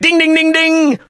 griff_ulti_vo_03.ogg